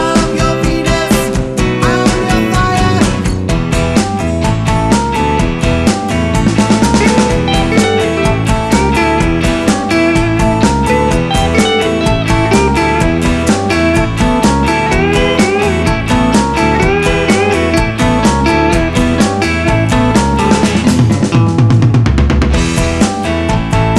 End Cut Down Pop (1970s) 2:39 Buy £1.50